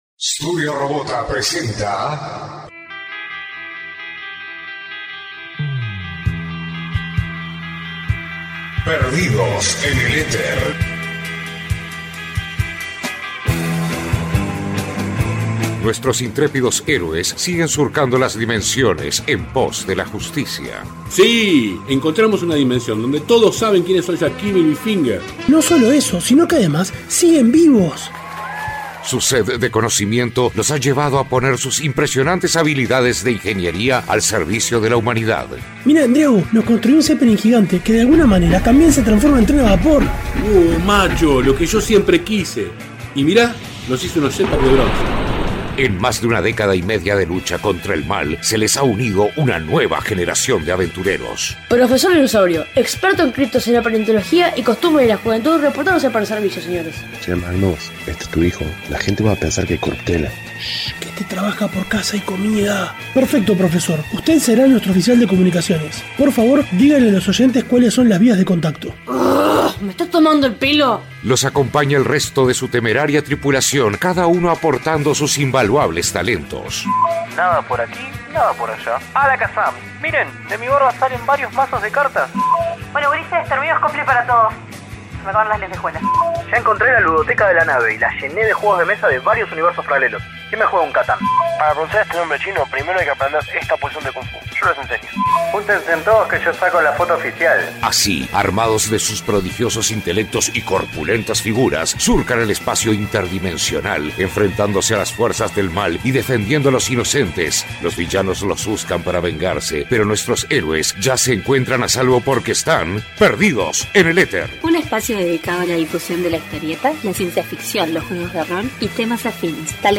Desde 2003, Perdidos En El Éter ha sido un programa radial (por momentos online, y por momentos en radio tradicional) dedicado a los comics y temas afines, como la ciencia ficción, los juegos de rol y la animación. Todos sazonado con buena musica y con mucho humor.